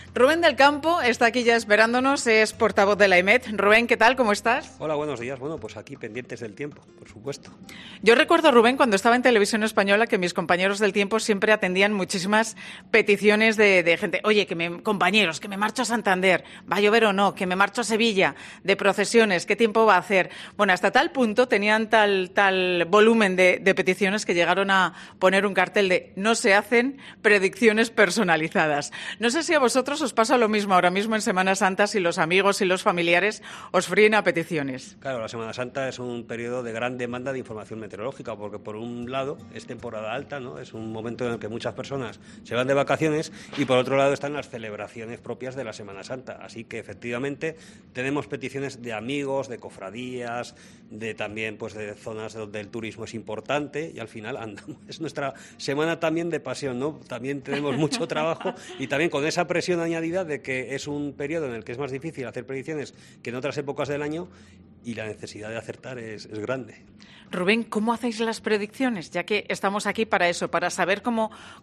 Y es que la directora del programa y comunicadora, Pilar García Muñiz, se ha ido hasta la sede de la Agencia Estatal de Meteorología (AEMET) para conocer el tiempo que hará.